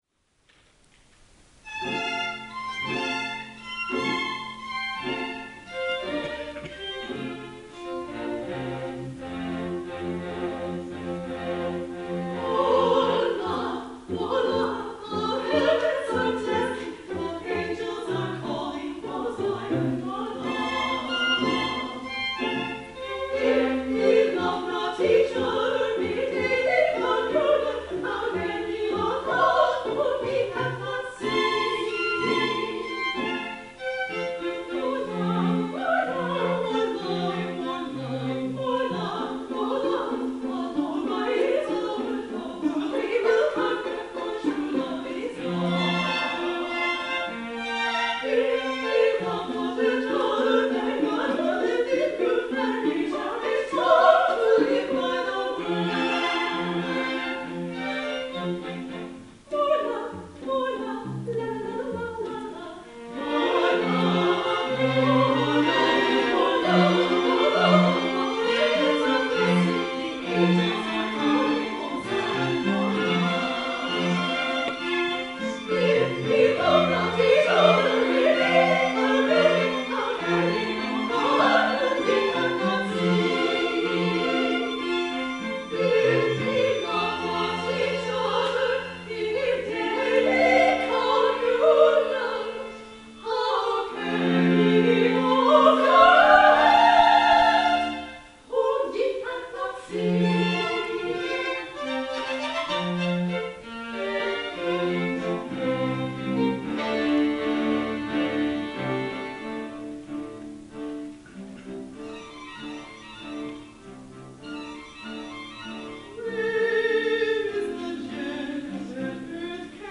for Soprano, Mezzo-Soprano, and String Quartet (2009)
soprano
mezzo-soprano
in a bouncing 6/8 meter suggestive of Shaker dancing tunes.